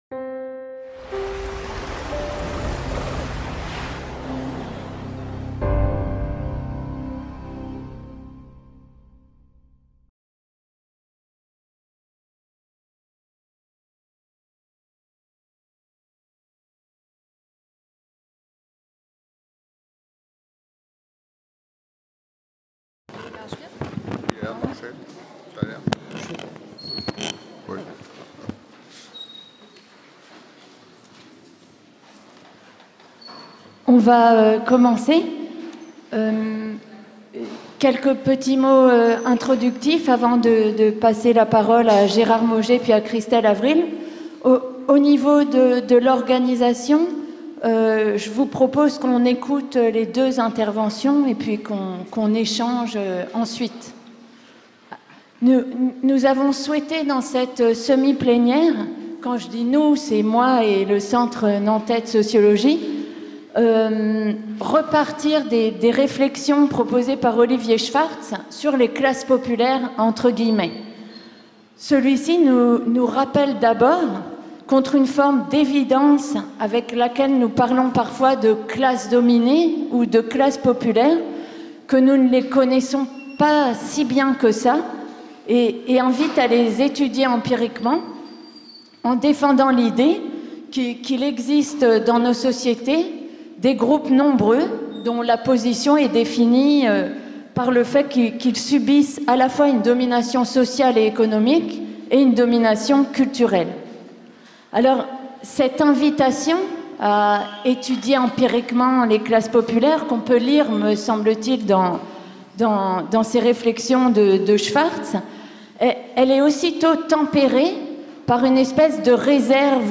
semi-plénière
L'UFR de Sociologie et le Centre Nantais de Sociologie (CENS) de l’Université de Nantes accueillaient du 2 au 5 septembre 2013 le 5e congrès international de l'association française de sociologie.